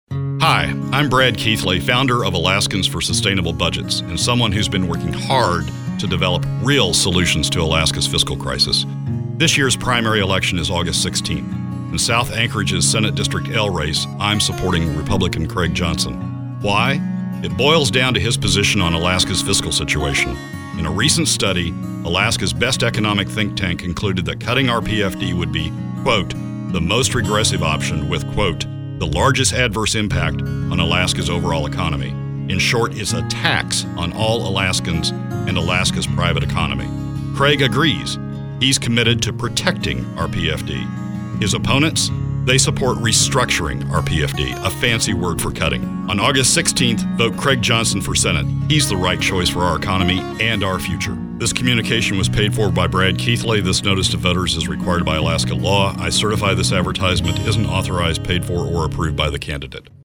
300x250 CJI am supporting Craig Johnson in the Republican Primary in Senate District L.   Here’s my radio spot — that goes up today and will run through the election — to prove it.